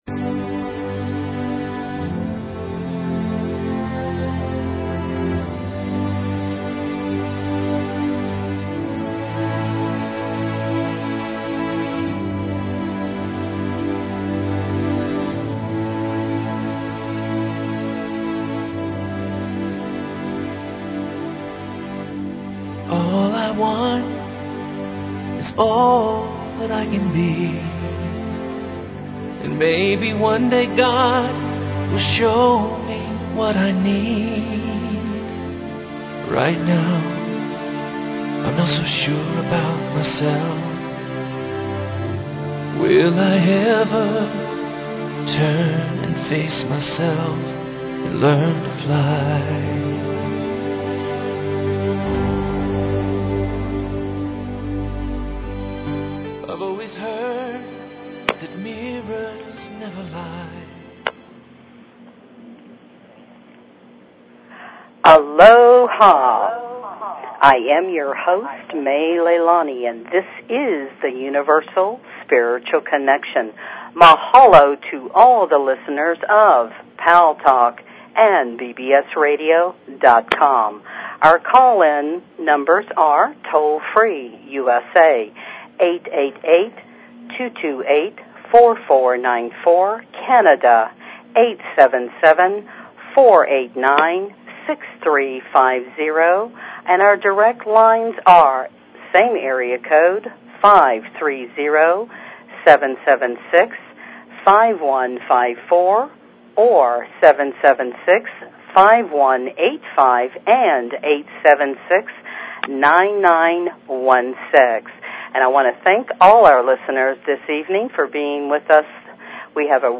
Talk Show Episode, Audio Podcast, Universal_Spiritual_Connection and Courtesy of BBS Radio on , show guests , about , categorized as